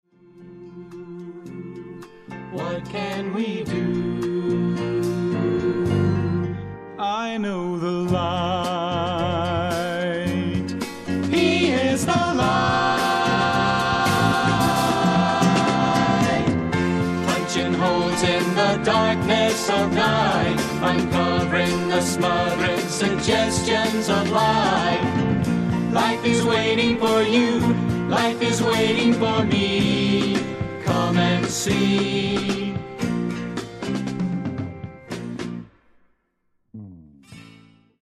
女性一人を含むテキサス出身の4人組